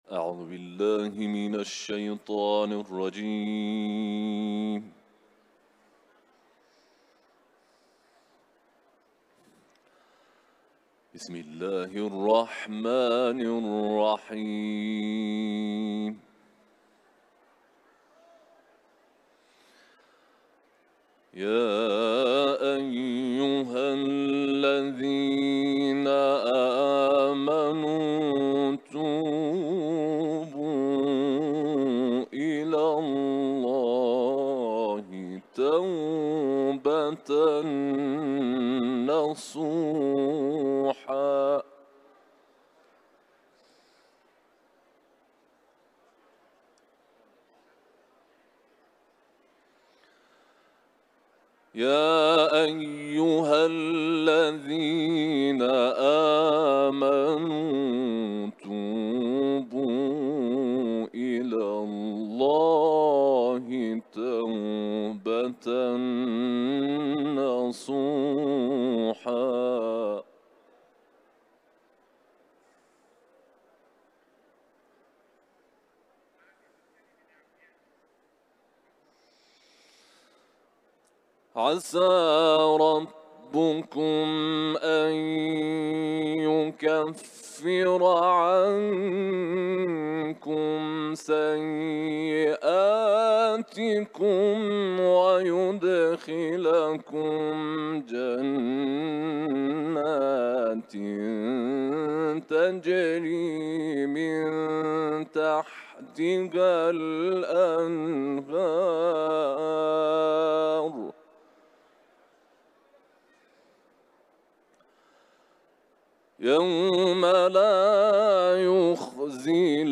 İranlı kâri